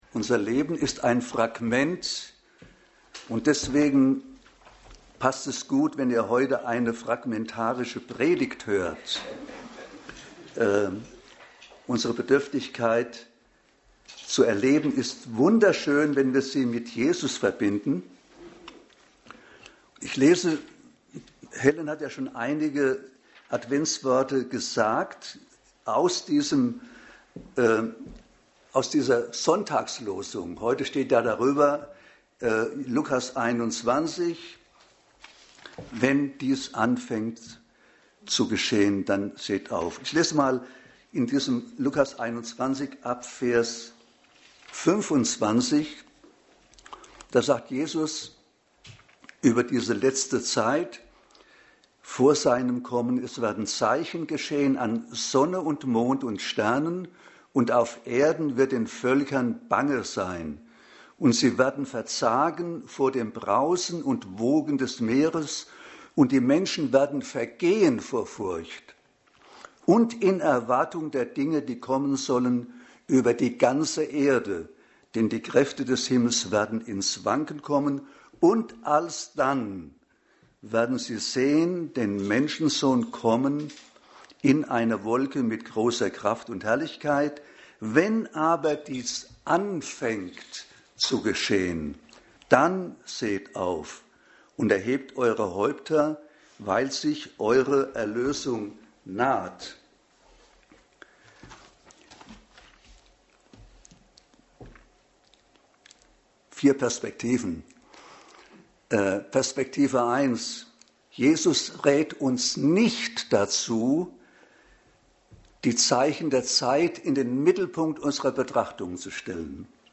Dienstart: Predigt Themen: Endzeit , Hoffnung , Wiederkunft Jesu « Thronfolger Freut euch